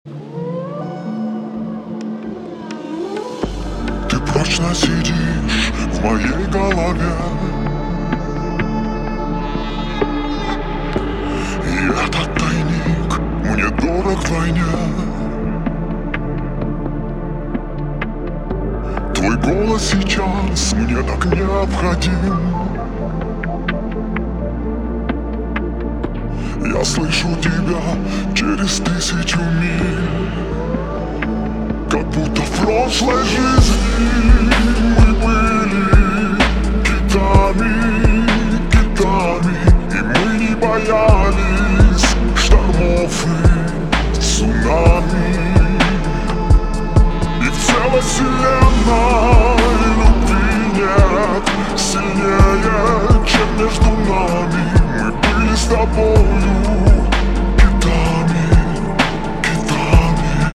• Качество: 320, Stereo
поп
грубый голос
цикличные